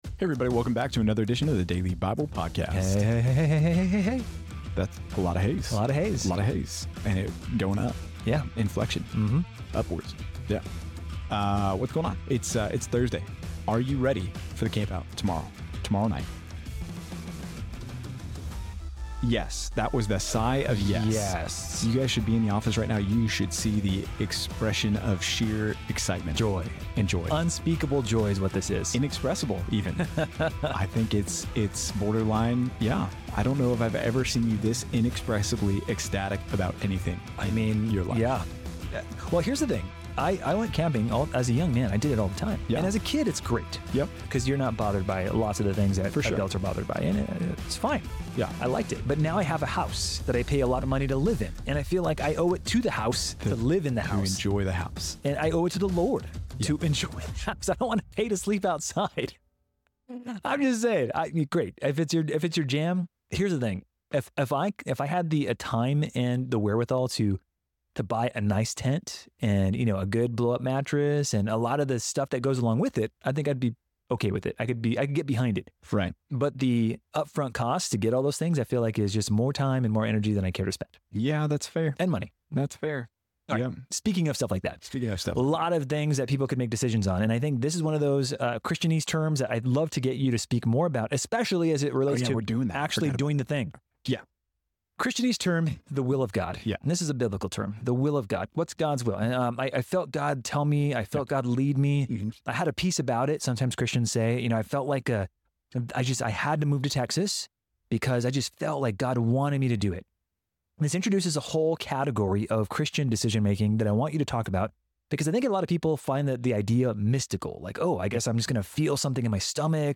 In this episode of the Daily Bible Podcast, the hosts discuss the complexities of understanding and navigating God's will for one's life, debunking common Christianese around the topic.